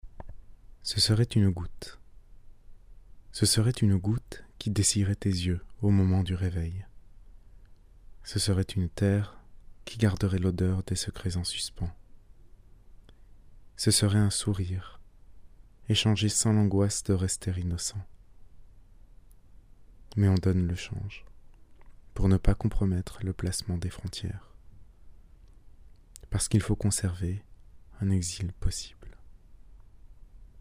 Il contient 23 poèmes lus par votre serviteur en mp3.